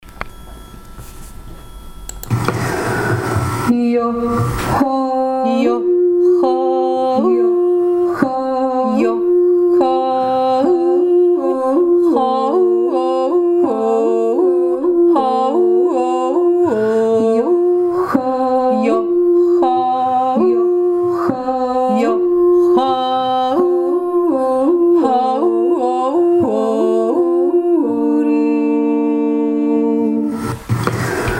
1. Stimme